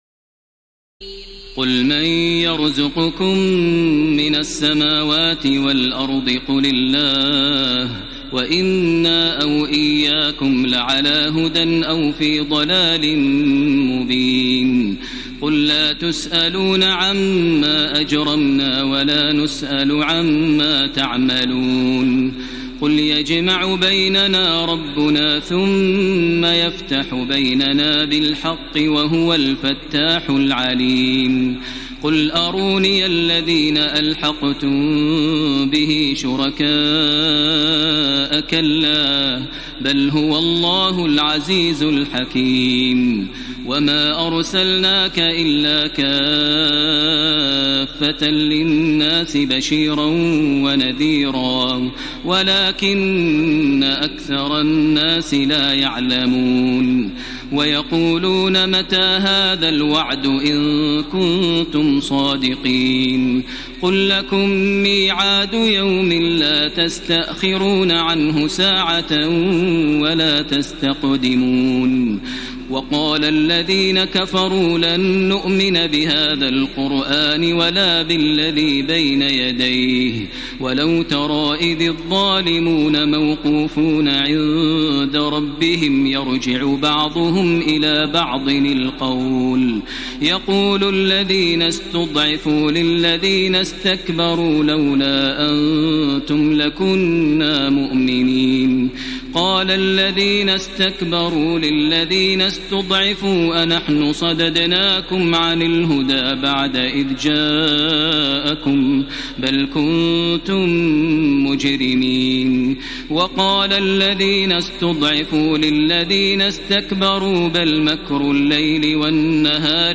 تراويح ليلة 21 رمضان 1433هـ من سور سبأ (24-54) وفاطر و يس(1-12) Taraweeh 21 st night Ramadan 1433H from Surah Saba and Faatir and Yaseen > تراويح الحرم المكي عام 1433 🕋 > التراويح - تلاوات الحرمين